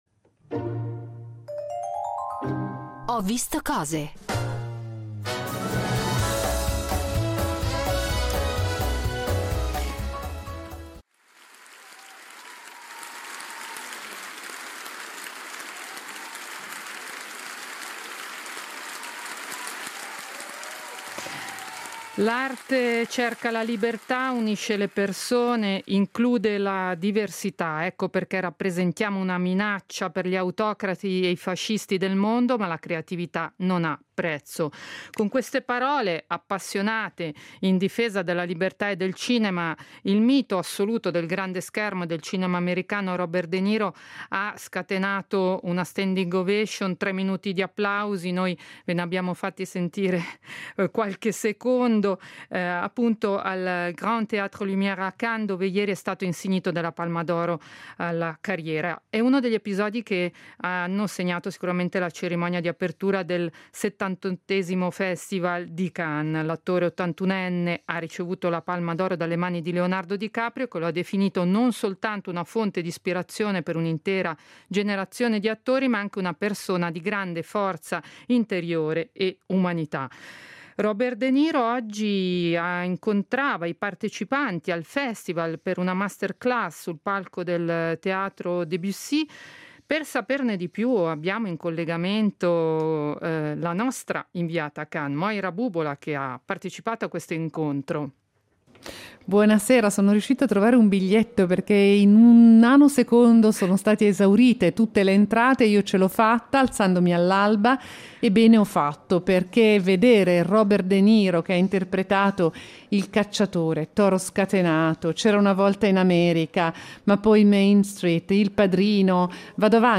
in diretta da Cannes